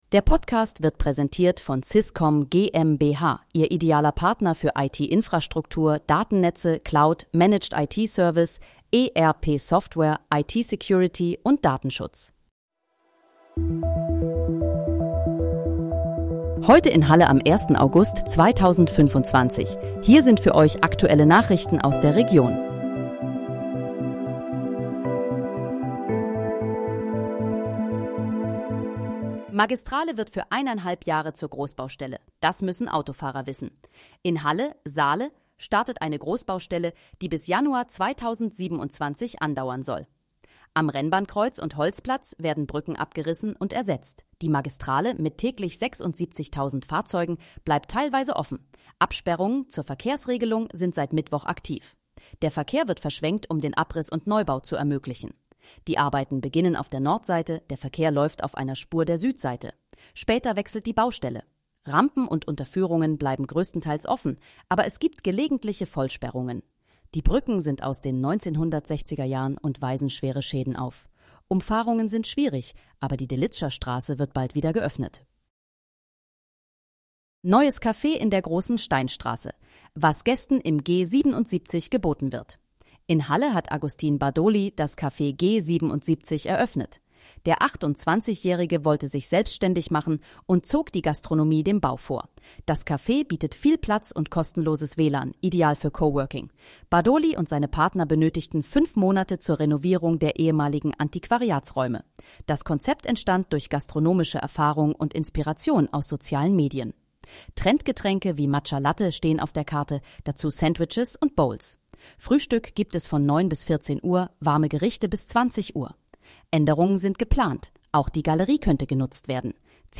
Heute in, Halle: Aktuelle Nachrichten vom 01.08.2025, erstellt mit KI-Unterstützung
Nachrichten